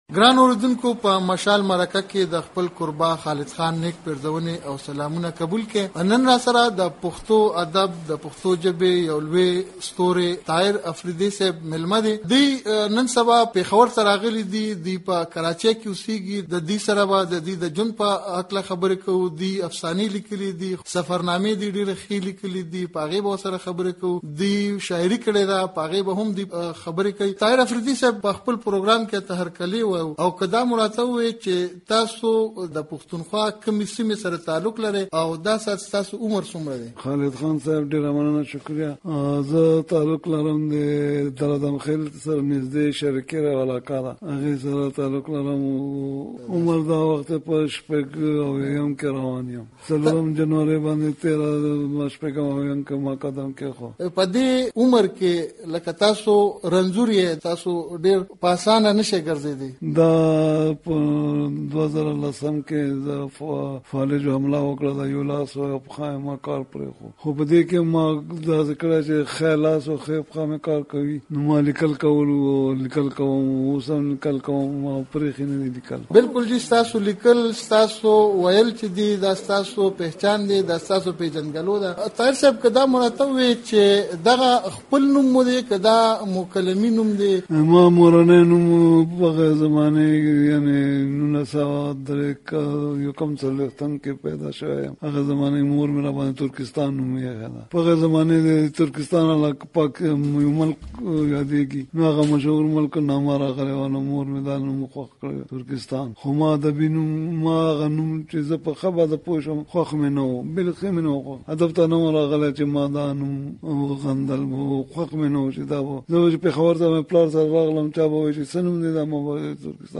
په مشال مرکه کې